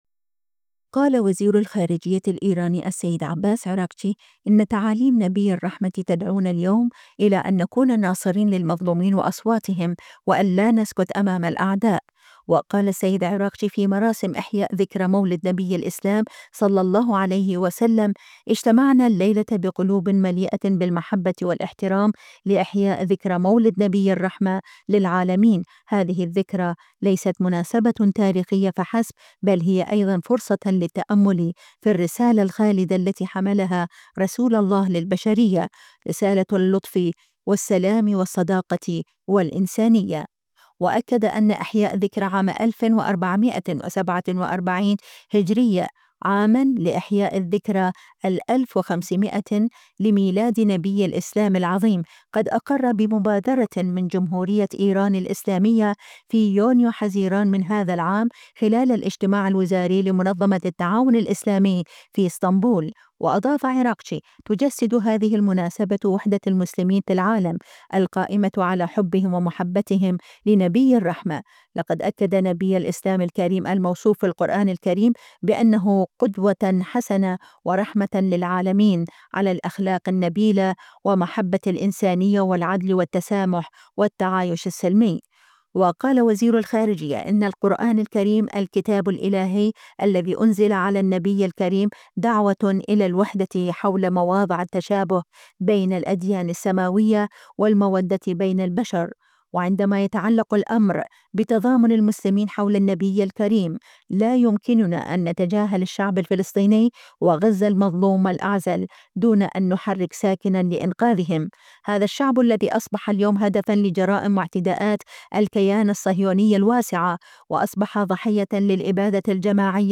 وقال السيد عراقجي في مراسم إحياء ذكرى مولد نبي الإسلام (صلى الله عليه وسلم): "اجتمعنا الليلة بقلوب مليئة بالمحبة والاحترام لإحياء ذكرى مولد نبي الرحمة للعالمين، هذه الذكرى ليست مناسبة تاريخية فحسب، بل هي أيضًا فرصة للتأمل في الرسالة الخالدة التي حملها رسول الله للبشرية؛ رسالة اللطف والسلام والصداقة والإنسانية".